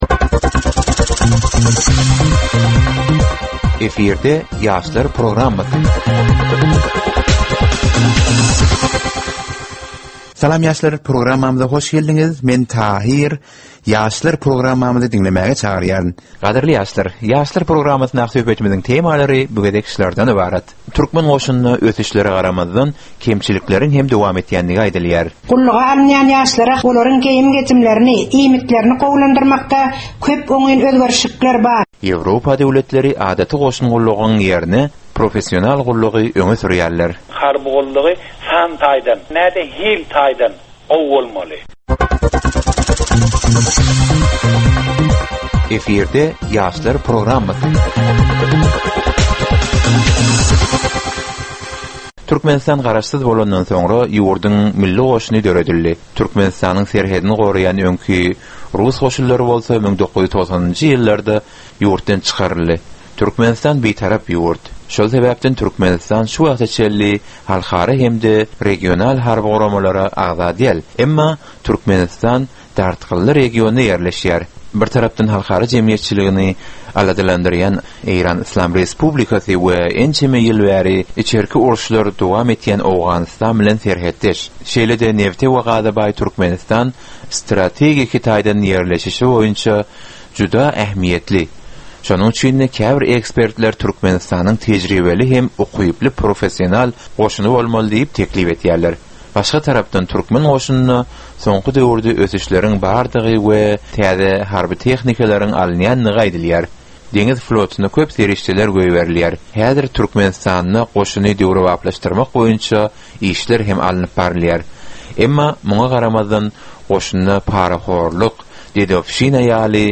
Gepleşigiň dowamynda aýdym-sazlar hem eşitdirilýär.